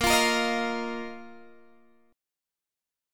Bbsus4 chord